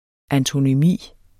Udtale [ antonyˈmiˀ ]